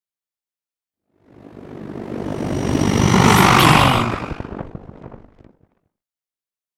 Scifi whoosh pass by chopper
Sound Effects
futuristic
pass by